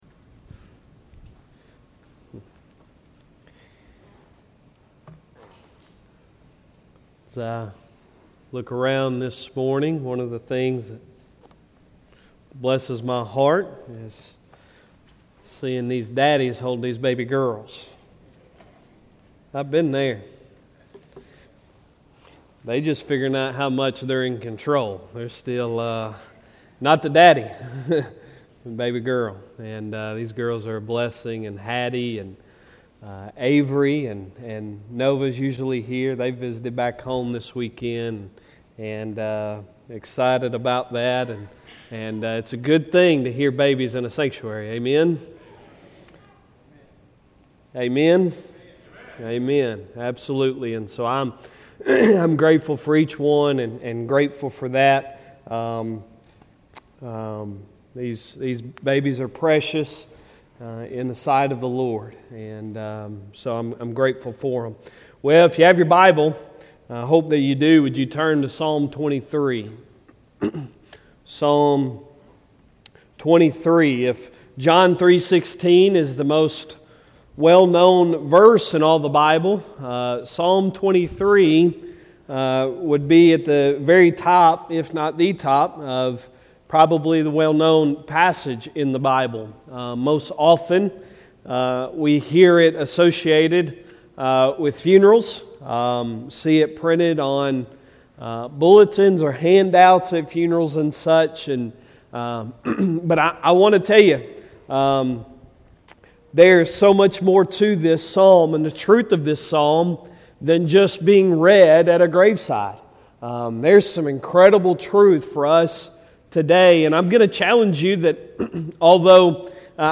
Sunday Sermon July 24, 2022